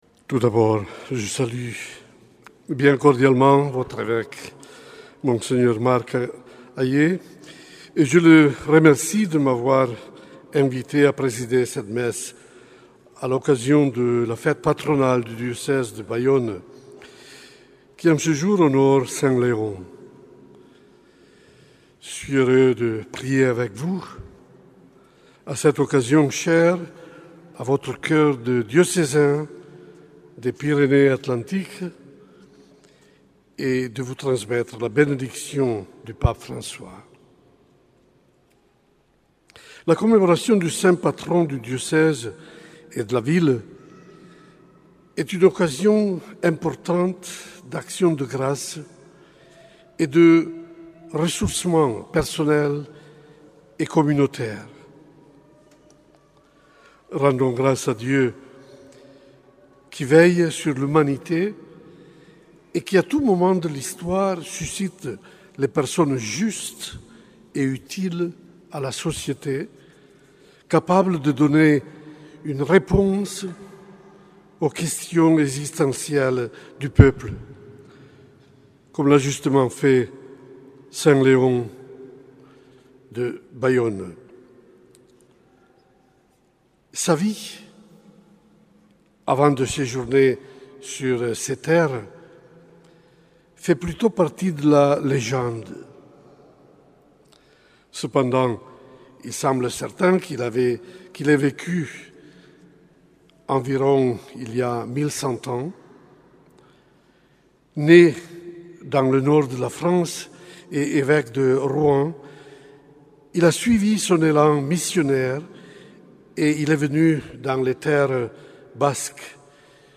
Homélie de Mgr Celestino Migliore, nonce apostolique en France le 7 mars en la cathédrale sainte Marie de Bayonne.